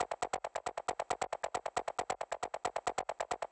• techno arp 31 shake.wav
techno_arp_31_shake_TFK.wav